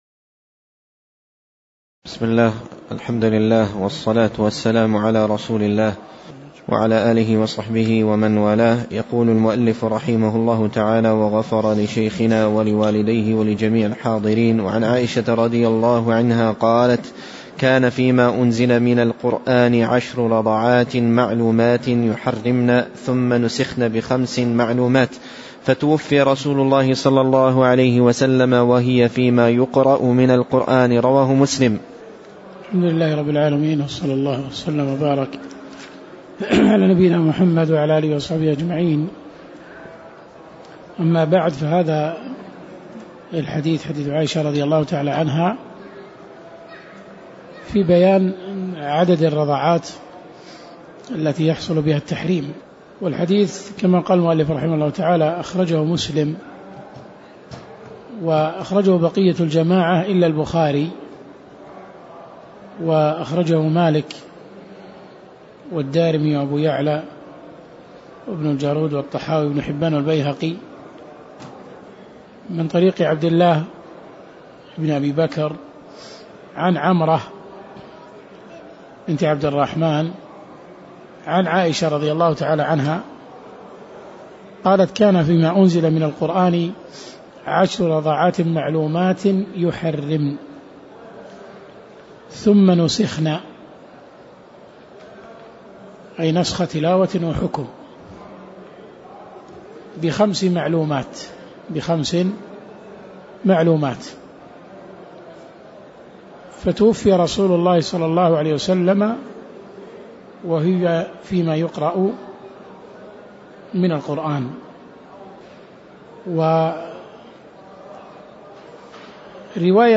تاريخ النشر ٢٥ صفر ١٤٣٩ هـ المكان: المسجد النبوي الشيخ